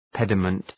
{‘pedəmənt}
pediment.mp3